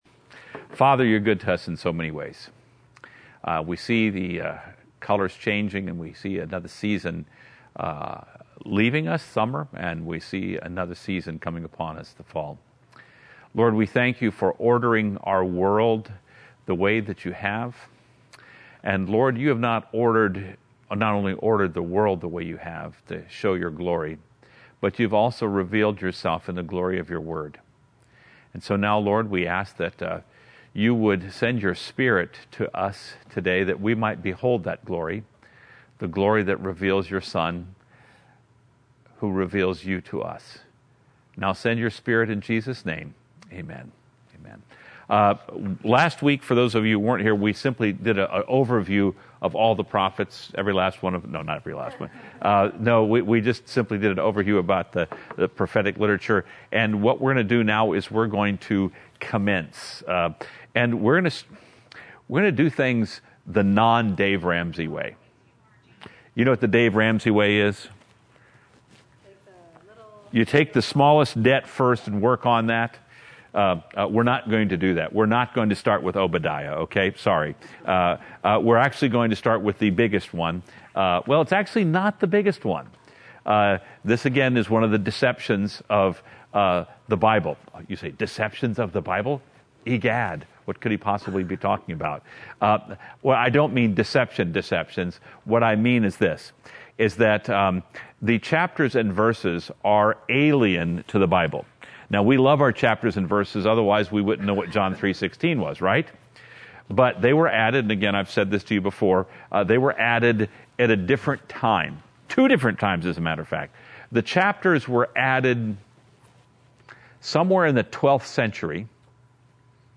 Experience the Word Bible Study